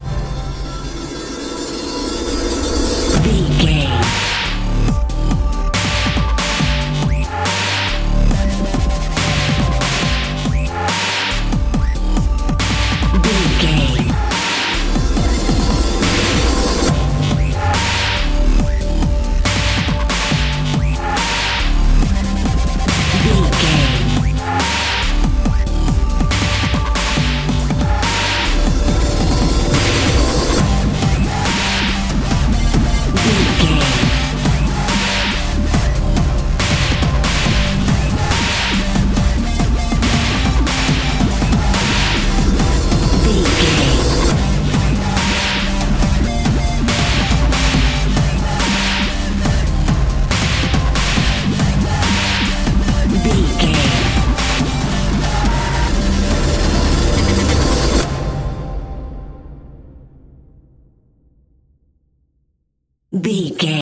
Aeolian/Minor
synthesiser
drum machine
orchestral
orchestral hybrid
dubstep
aggressive
energetic
intense
strings
drums
bass
synth effects
wobbles
driving drum beat
epic